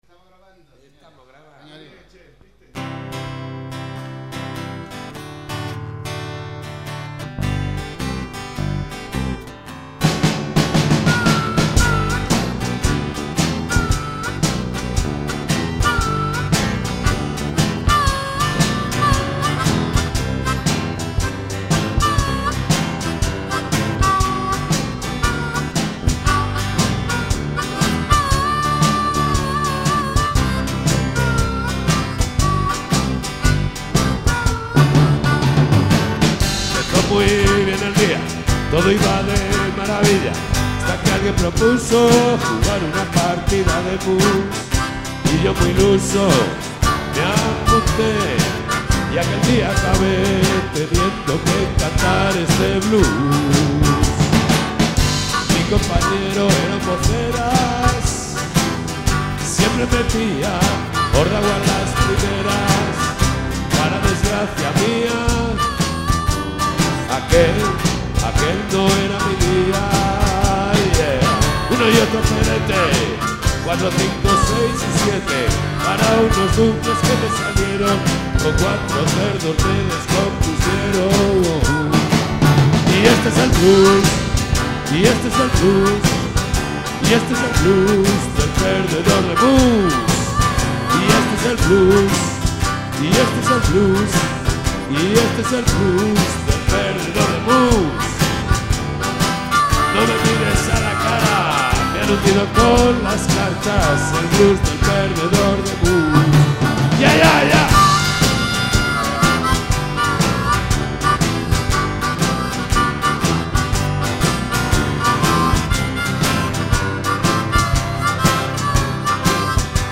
Guitarra acústica y voz
Batería
Bajo
Armónica y guitarra eléctrica